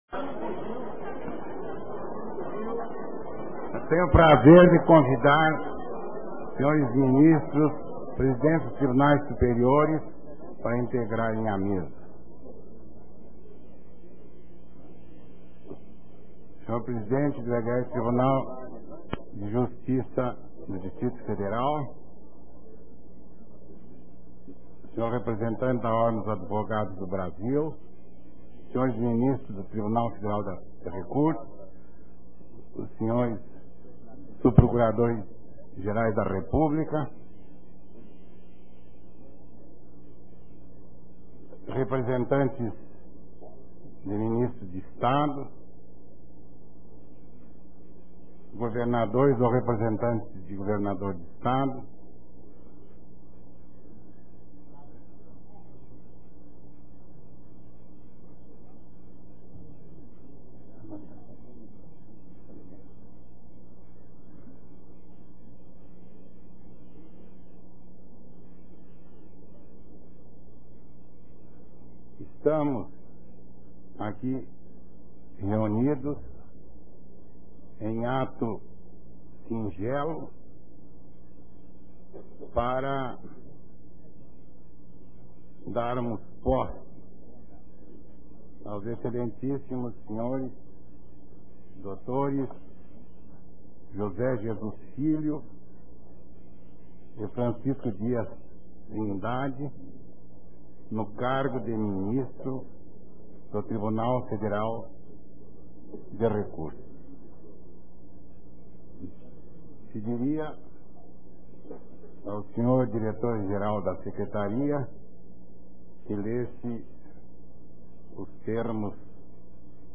Áudio da Cerimônia de Posse do Ministro José de Jesus no Tribunal
audio-posse-jose-jesus.wma